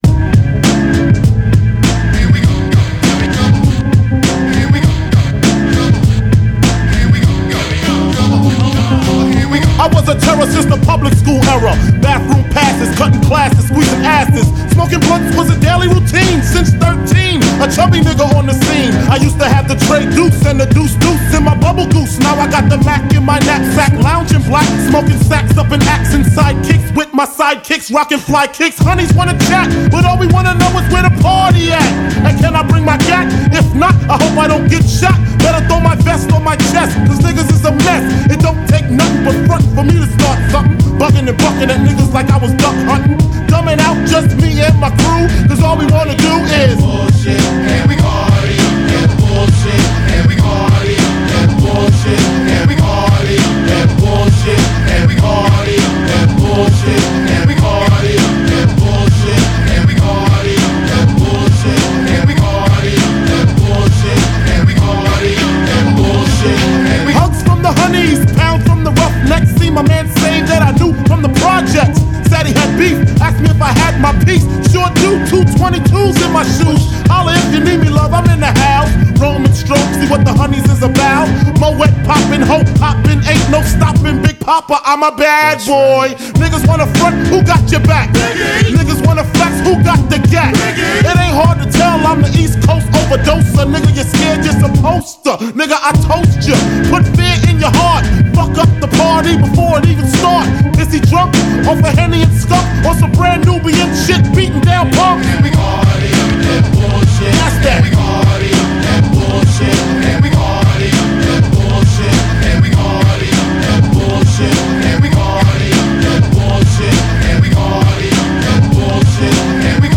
イントロからかっこいいです。